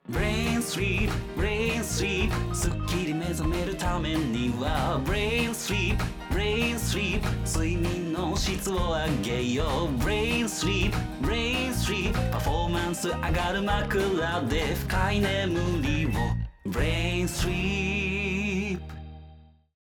楽曲CM